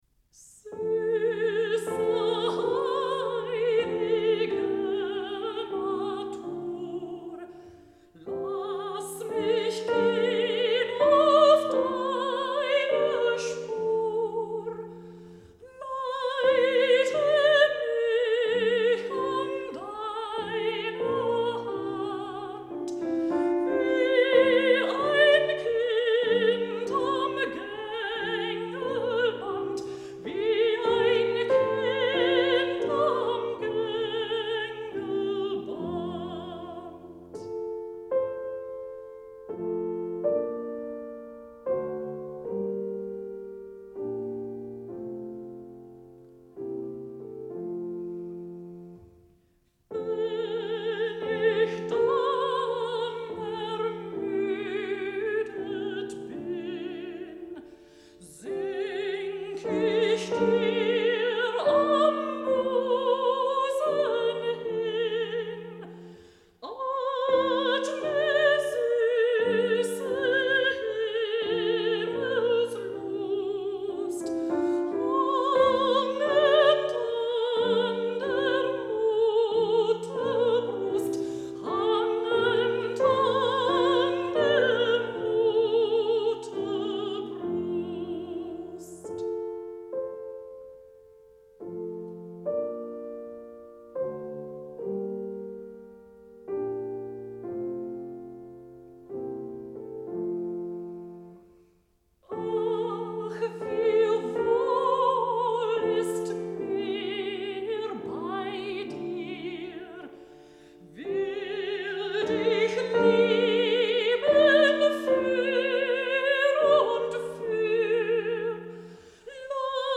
Í þáttunum er leikin sígild tónlist úr ýmsum áttum. Tíndir eru saman fróðleiksmolar um tónlistina og höfunda hennar og skyggnst í söguna á bak við verkin.